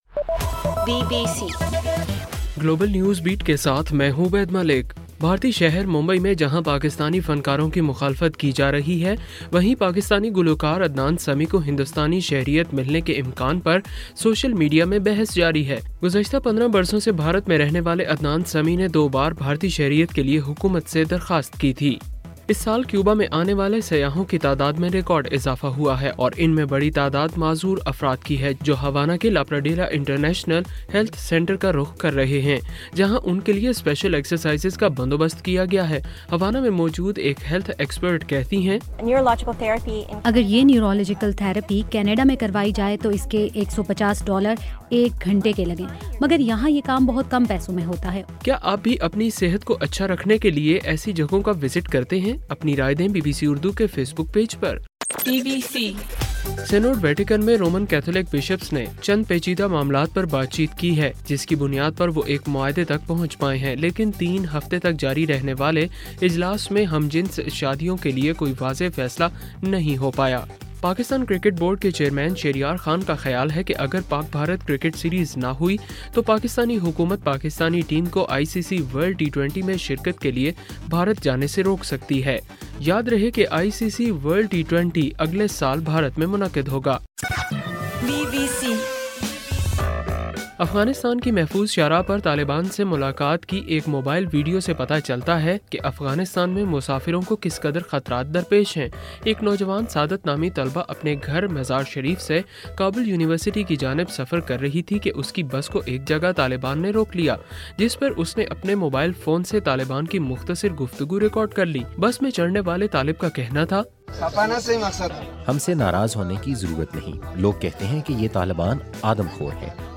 اکتوبر 25: صبح 1 بجے کا گلوبل نیوز بیٹ بُلیٹن